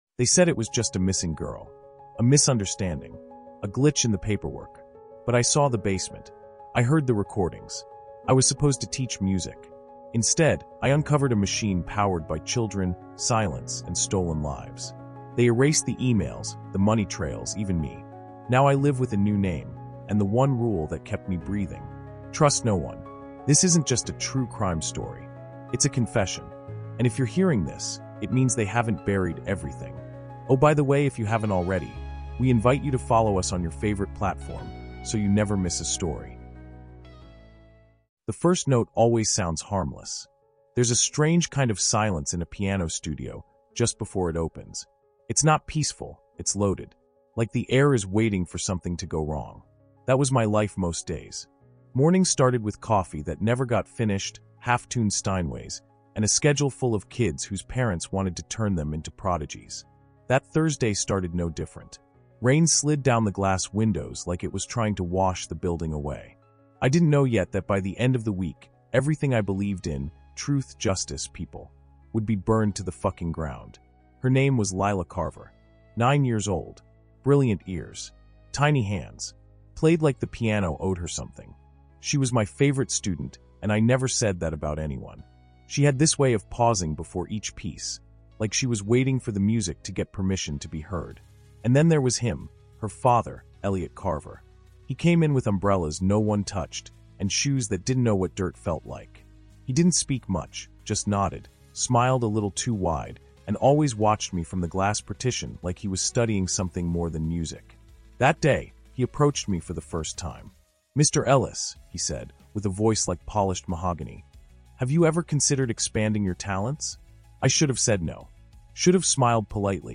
Now, he’s the only living witness to a criminal empire disguised as a music school and masked by charitable lies. The Crime That Convinced Me to Trust No One is a gripping, first-person true crime audiobook that pulls listeners into the heart of a murder tale buried beneath grants, fake nonprofits, and offshore accounts.